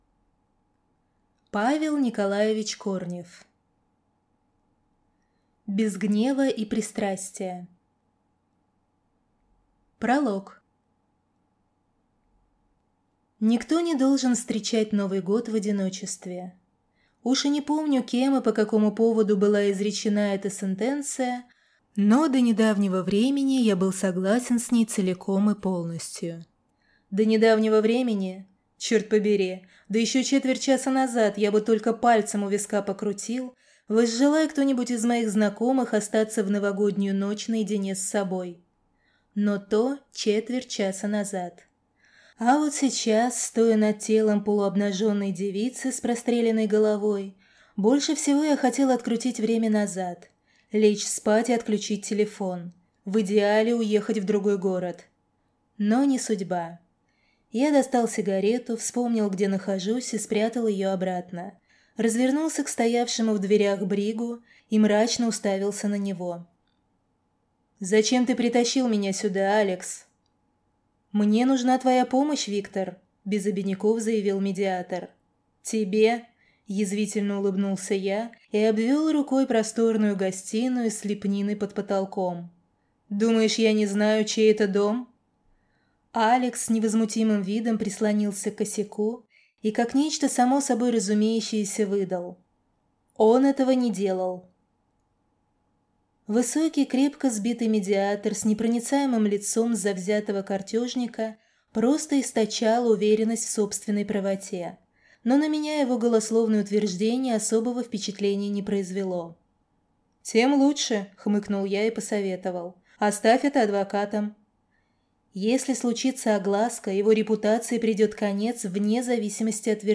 Аудиокнига Без гнева и пристрастия - купить, скачать и слушать онлайн | КнигоПоиск